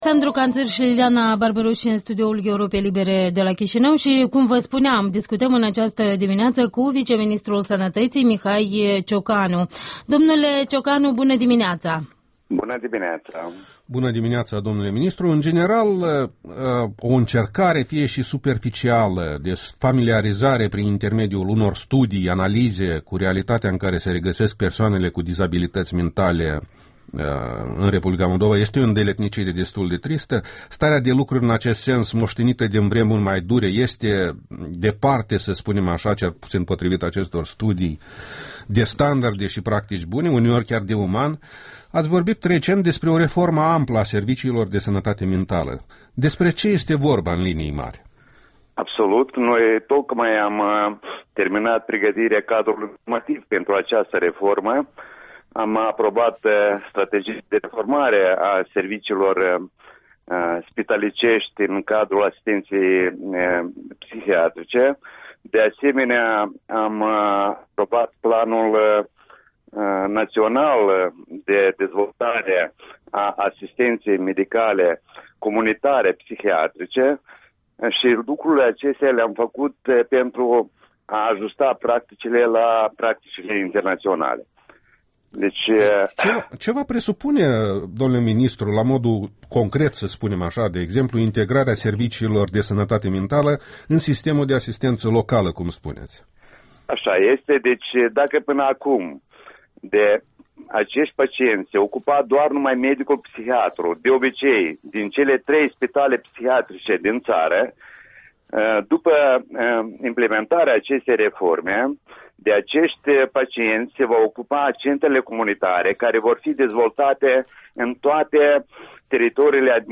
Interviul dimineții: cu prof. Mihail Ciocanu, ministru adjunct al Sănătății